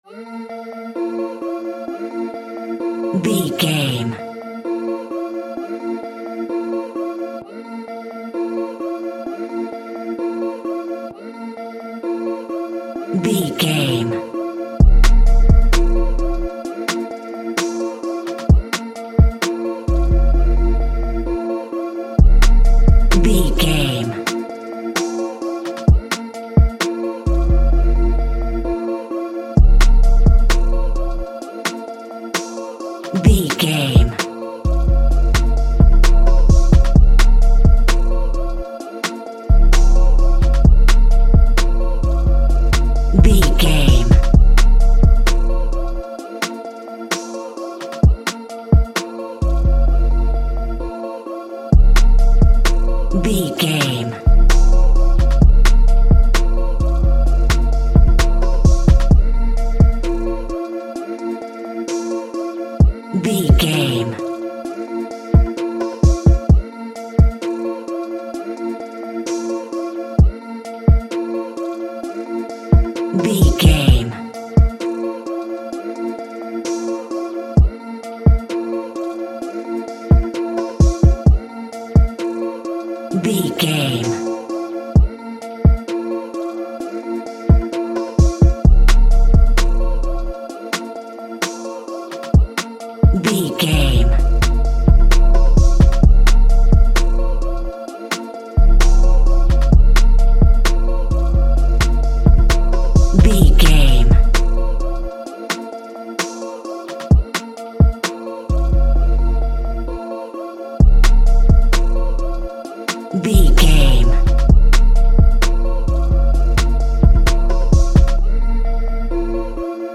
Ionian/Major
aggressive
intense
driving
dramatic
energetic
dark
drums